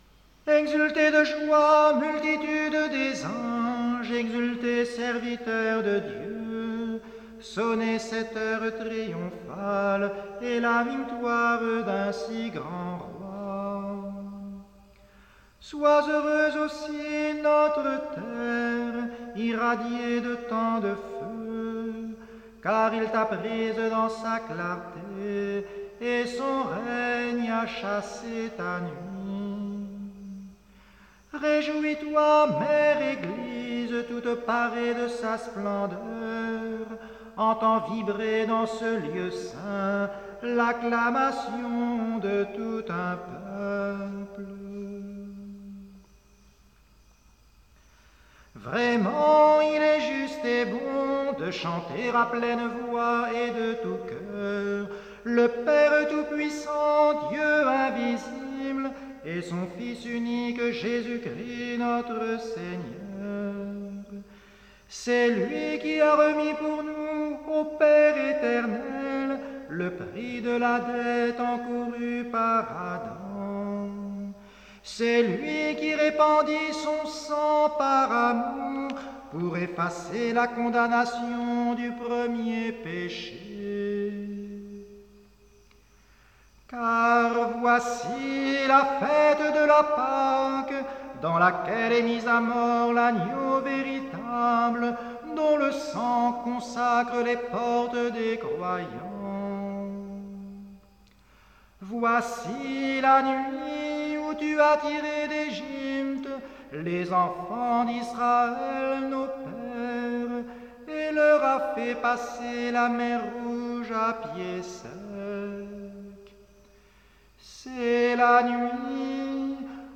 À Pâques, chantons l’